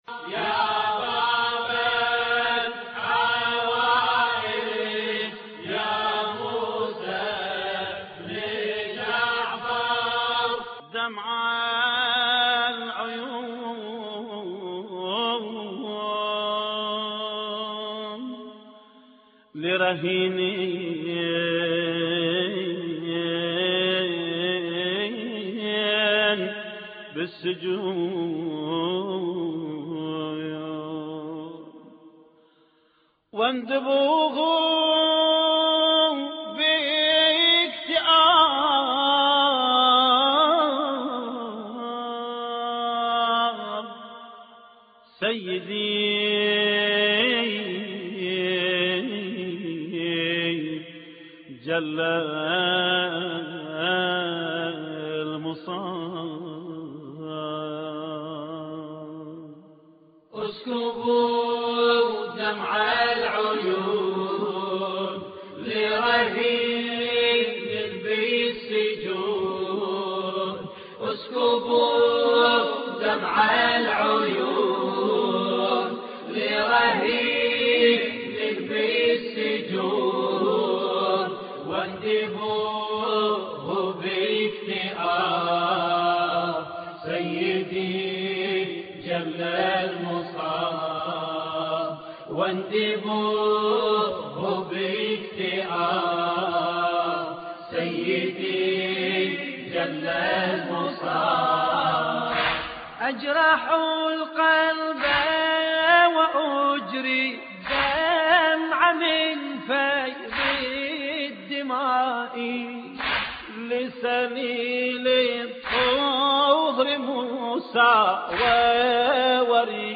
مراثي الامام الكاظم (ع)